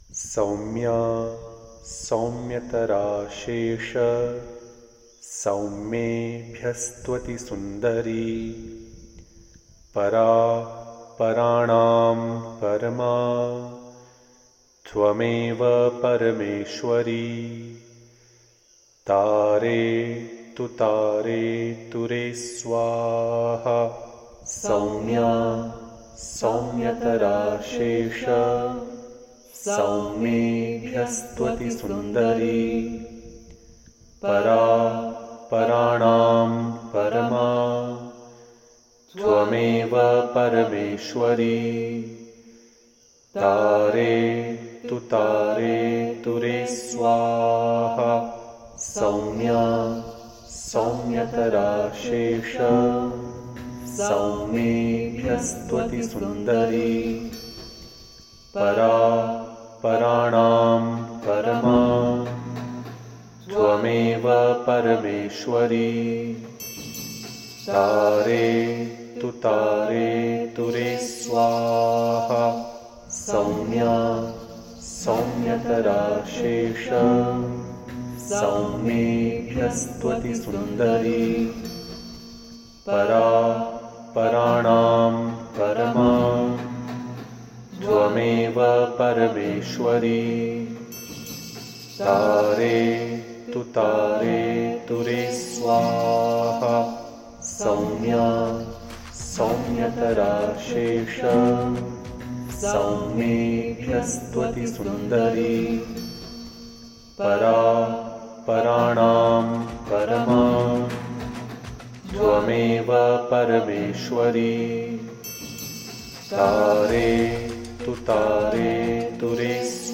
Saumya Mantra Chanting